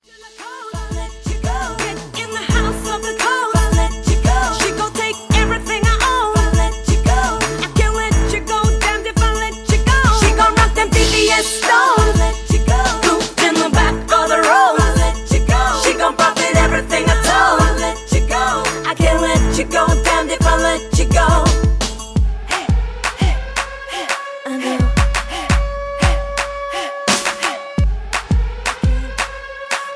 Karaoke Mp3 Backing Tracks